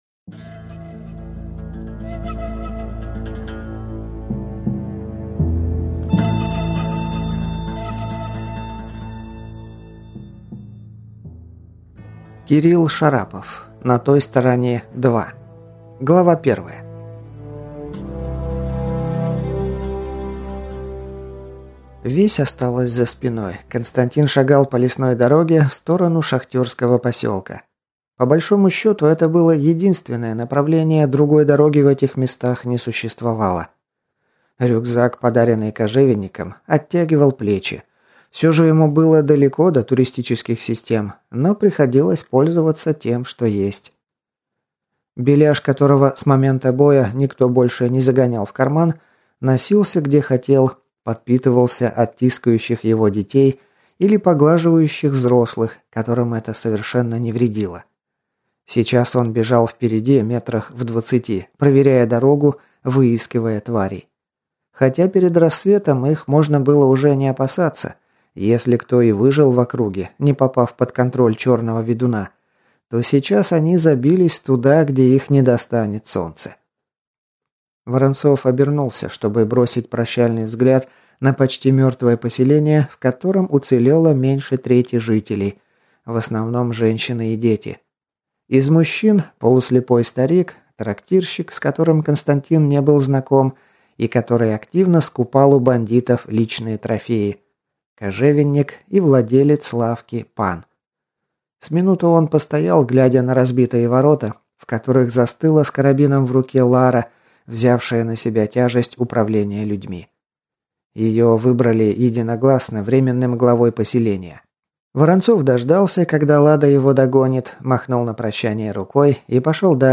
Аудиокнига На той стороне. Книга 2. Пыль дорог | Библиотека аудиокниг